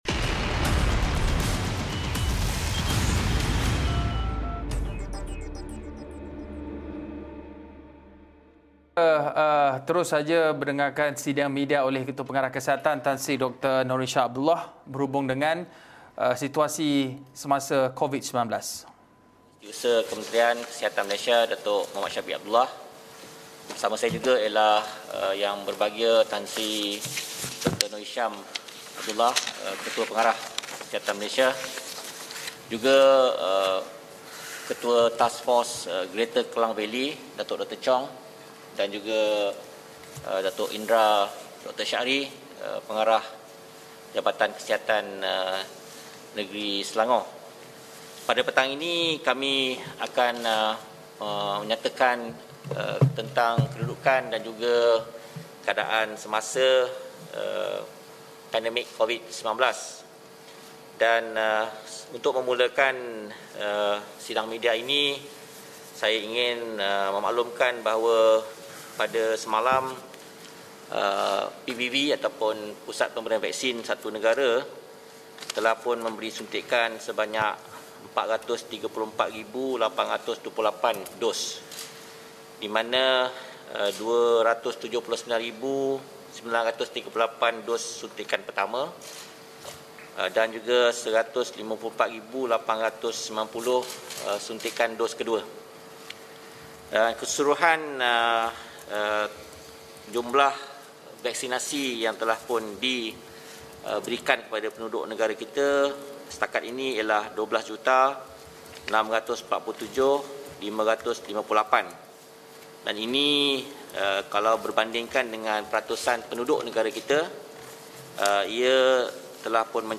[TERKINI] Sidang media Kementerian Kesihatan Malaysia
Ikuti sekarang sidang media oleh Menteri Kesihatan, Datuk Seri Dr Adham Baba dan Ketua Pengarah Kesihatan, Tan Sri Dr Noor Hisham Abdullah berhubung peningkatan kes jangkitan COVID-19 di Malaysia.